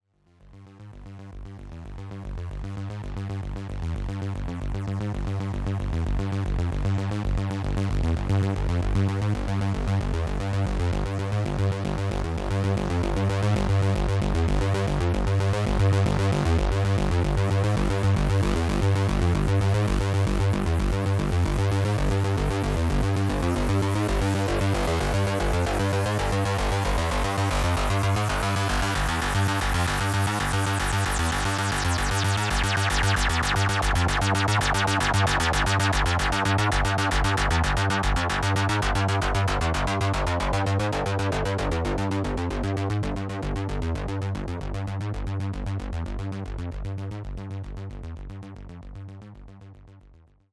I used the onboard distortion, filter res, and FEG decay, no post processing.